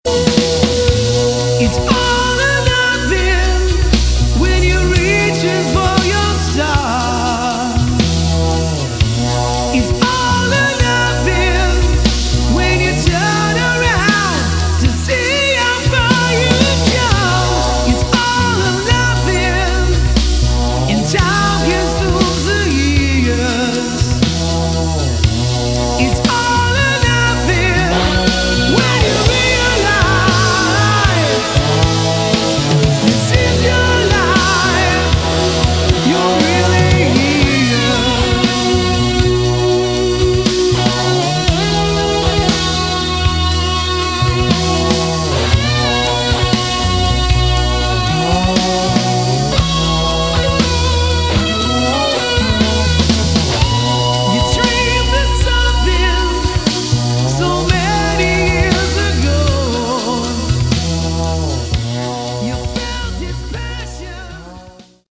Category: AOR / Melodic Rock
lead vocals, keyboards, rhythm guitar
bass guitar, backing vocals
drums, percussion
lead guitar, spanish guitar, backing vocals